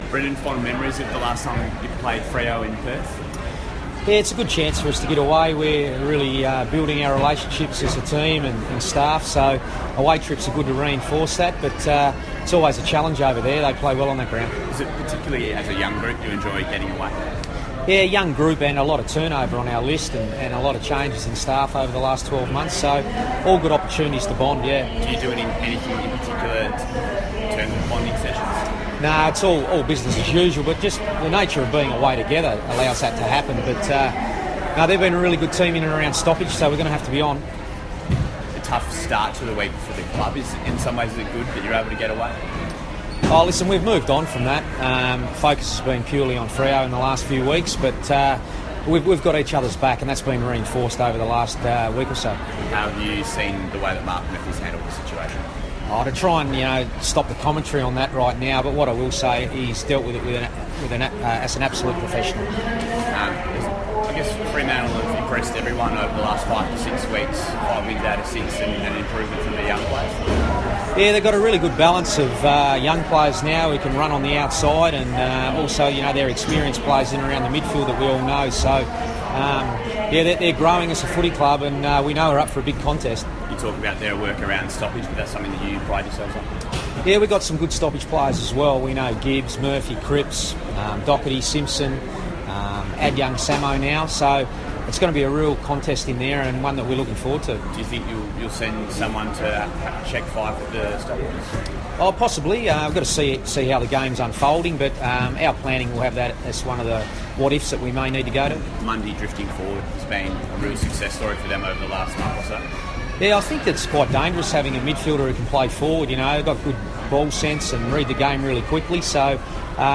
Brendon Bolton press conference - May 20
Carlton coach Brendon Bolton speaks to the media at Melbourne Airport ahead of the Blues' flight to Perth to take on Fremantle.